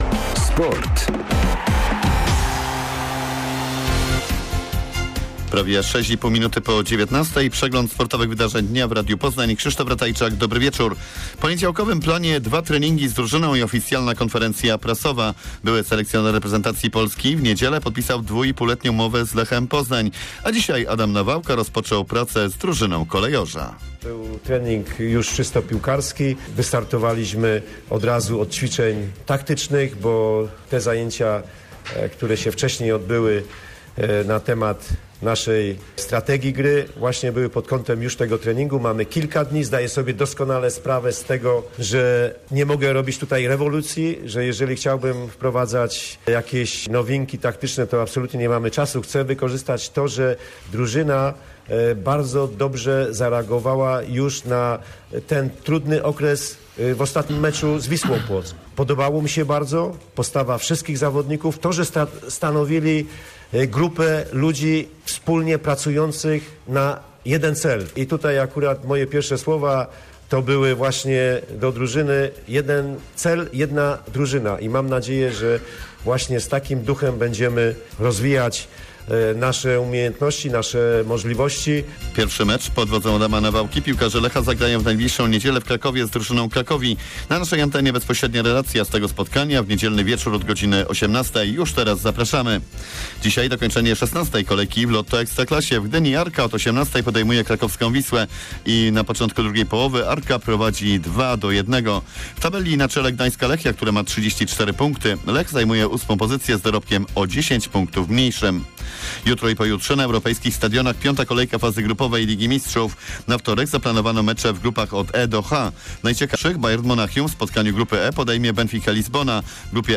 26.11. serwis sportowy godz. 19:05